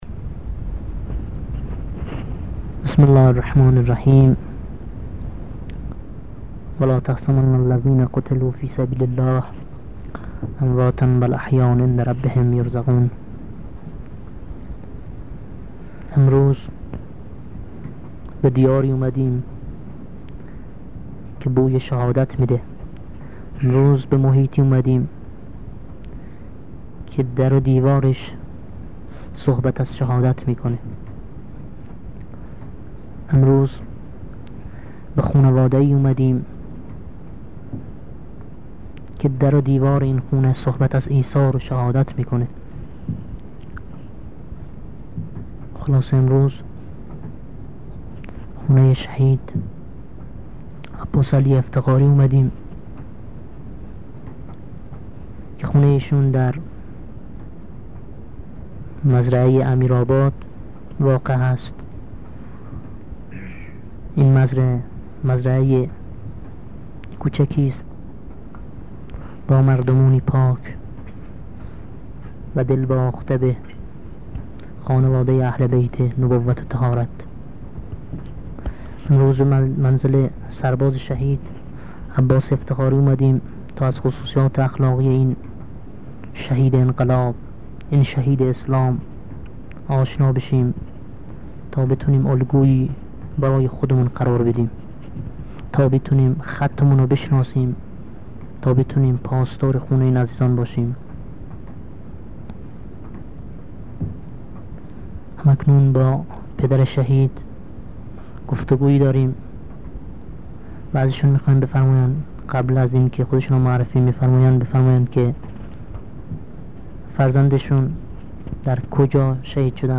مصاحبه با خانواده شهید